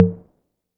808 H Tom Modulated.wav